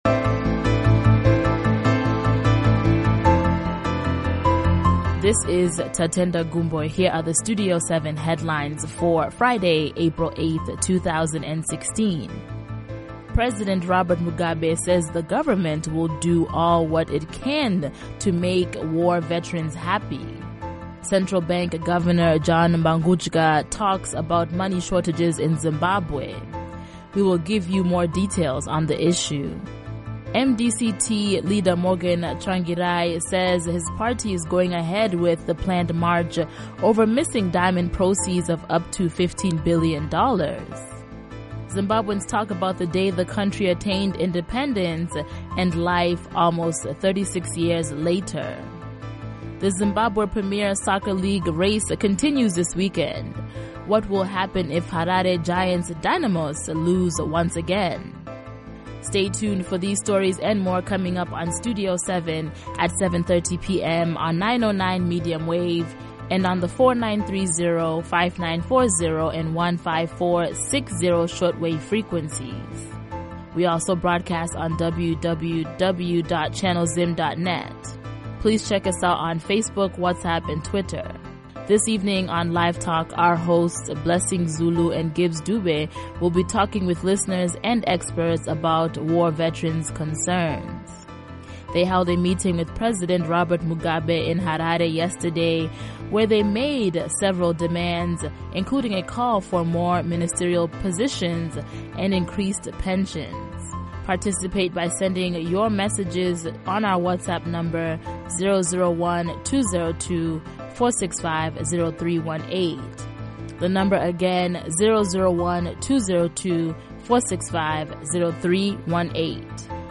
Headlines